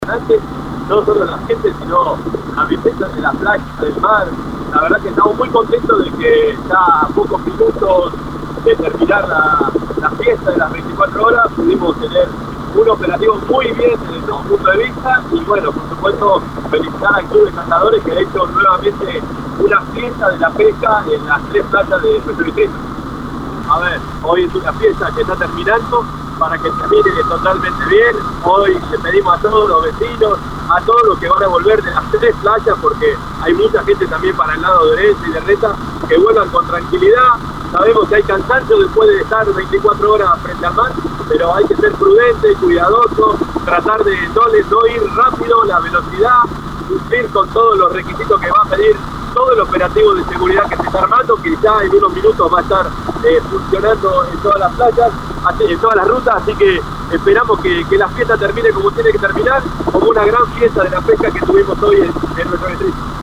Cerramos “Las 24” desde el helicóptero junto al Intendente Pablo Garate (audio y videos)